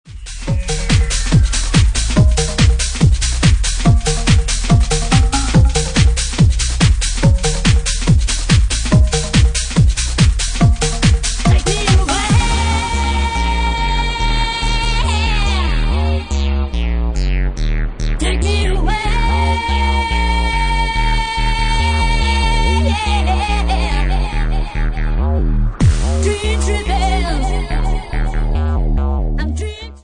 Bassline House at 142 bpm